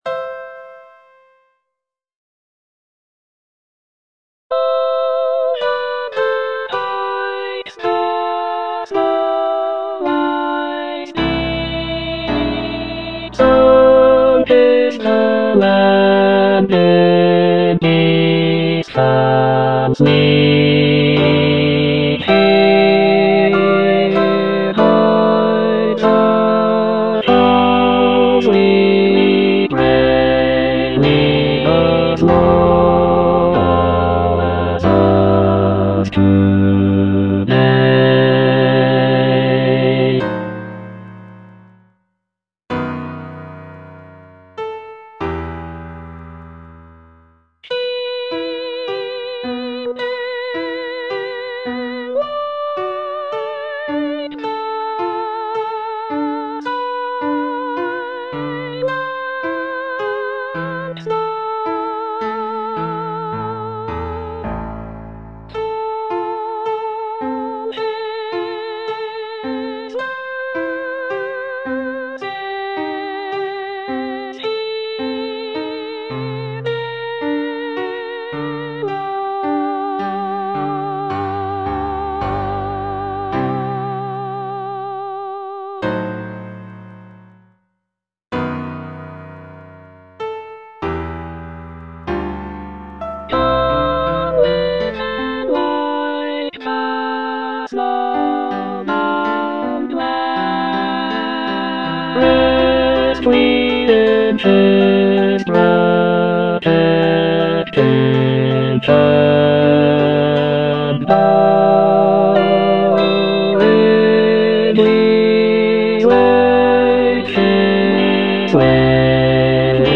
E. ELGAR - FROM THE BAVARIAN HIGHLANDS Aspiration (bass I) (Emphasised voice and other voices) Ads stop: auto-stop